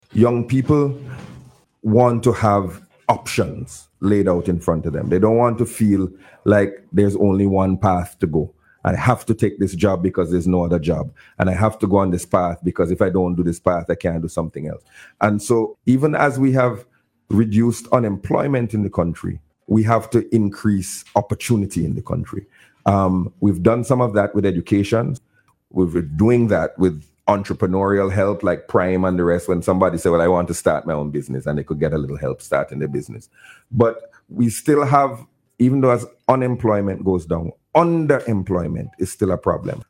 Speaking on Radio yesterday, Minister Gonsalves said unemployment is at its lowest in the country’s history.